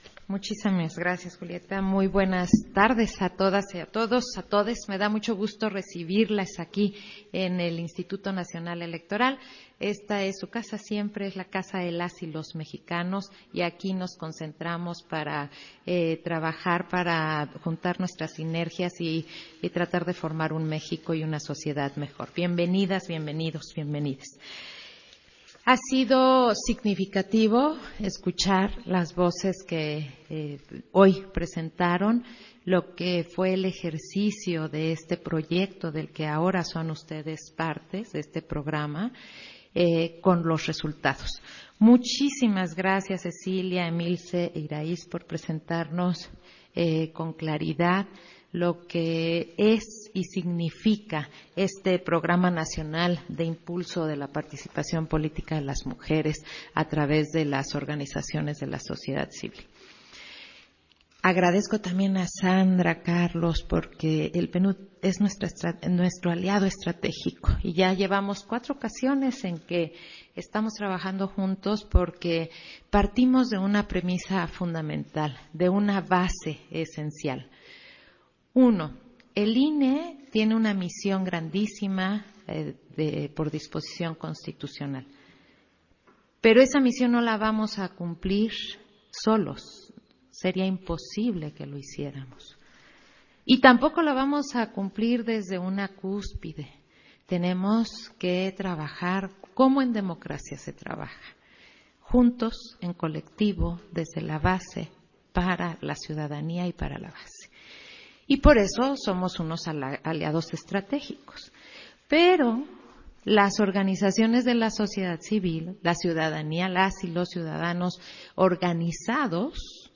Intervención de Claudia Zavala, en la firma de convenios de colaboración del Programa Nacional de Impulso a la Participación Política de Mujeres a través de organizaciones de la sociedad civil 2022